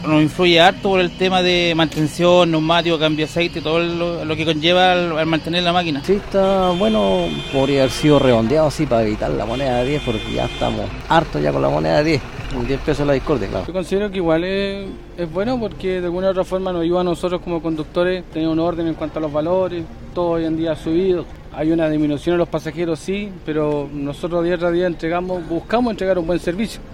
Por su parte, al ser consultados por La Radio, los choferes de microbús señalaron que ven con buenos ojos la medida, esperando que los usuarios puedan adaptarse a la nueva tarifa.